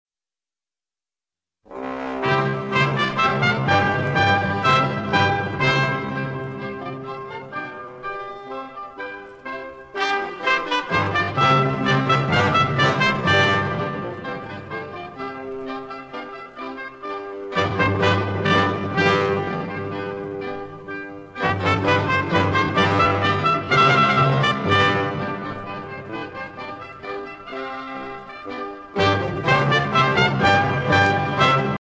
S’ha baixat el to a Do i el tempo s’ha reduït un 20% per facilitar l’aprenentatge amb el xilòfon virtual.
delalande-aria-en-eco-en-do-i-lent.mp3